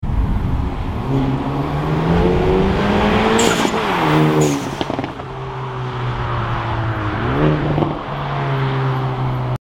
Rocking the AEM intake combined sound effects free download
Rocking the AEM intake combined with a HKS BoV and making all the noises now 😍